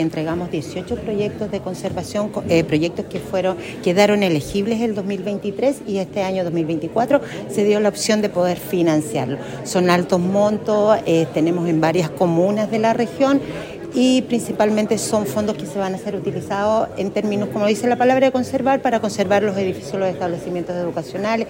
Mientras que, la seremi de Educación en La Araucanía, Marcela Castro, señaló que se destinaron más de $7 mil 358 millones para 18 proyectos en la región.